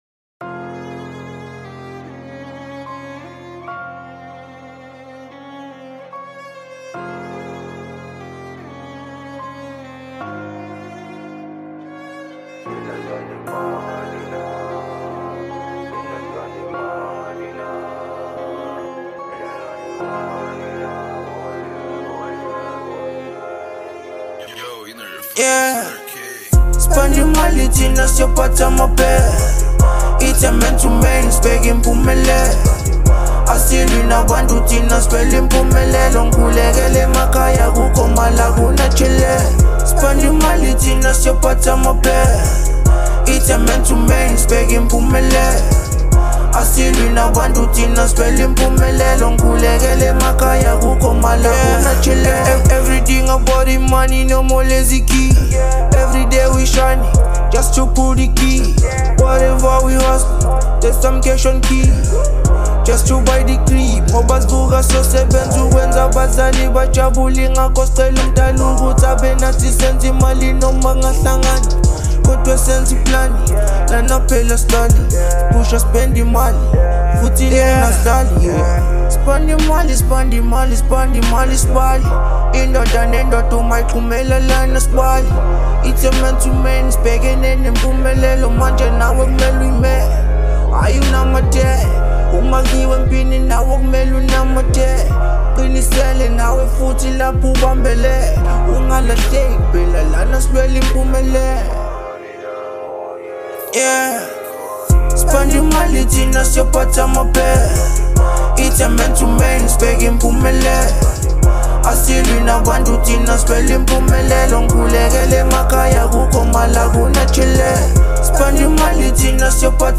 02:40 Genre : Trap Size